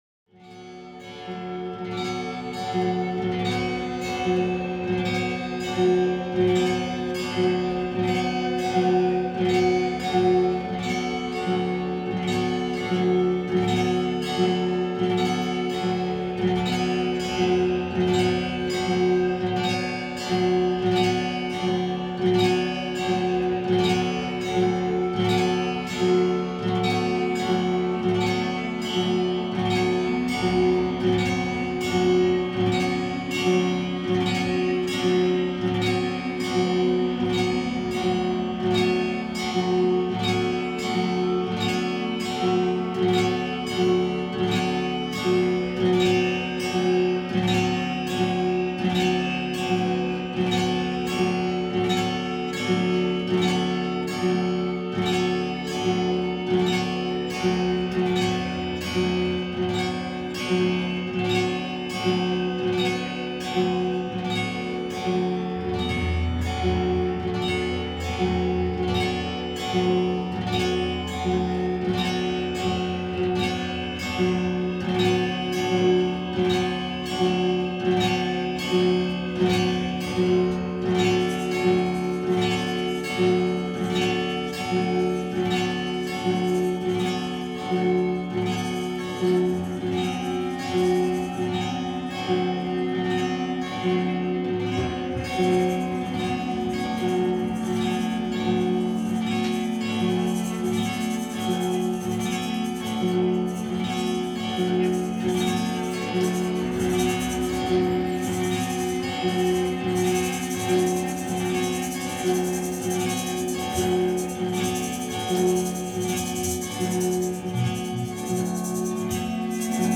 delicious distortion guitar
na área do folk rock psicadélico experimental e ambiental.